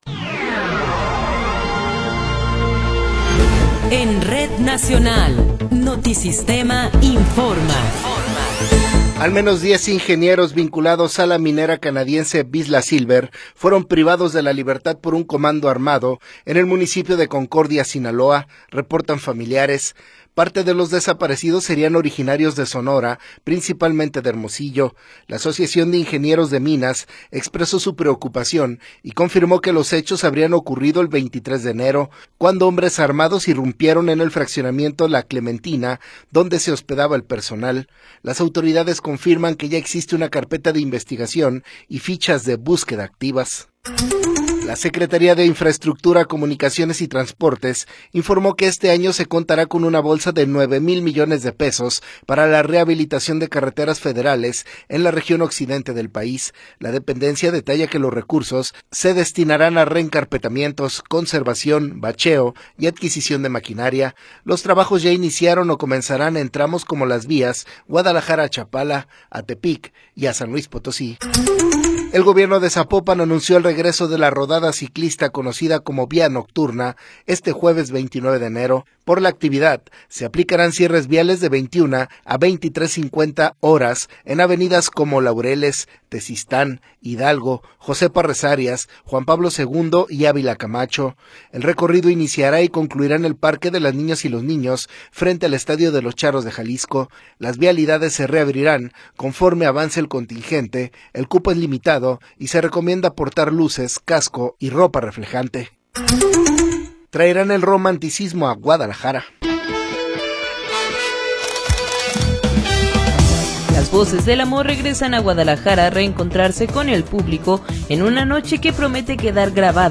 Noticiero 19 hrs. – 28 de Enero de 2026